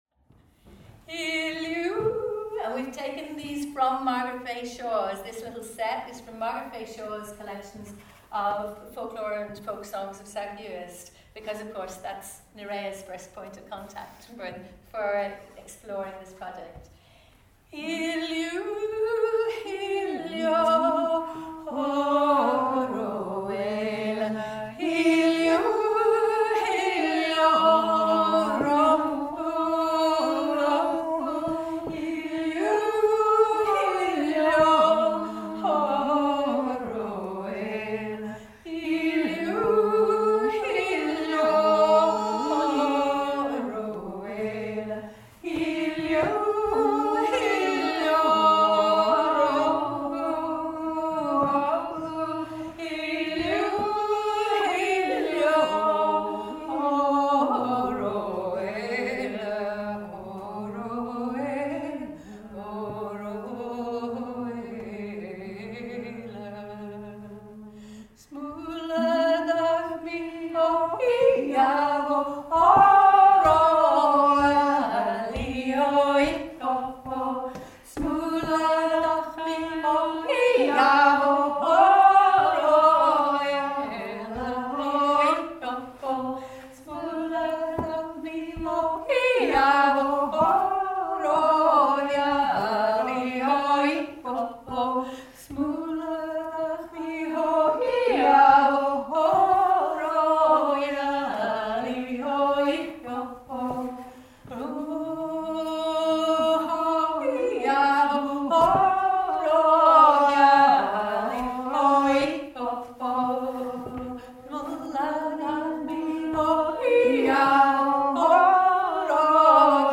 Our first important step forward was the decision to work with voice – to search for the ‘raw expression’ of grief, bypassing many beautiful renditions of laments and elegies.
We have taken vocables from many sources expressing loss and sorrow, and used them to weave a continuous thread, all connecting our audacious attempts at recreating the sound of the keeners of old.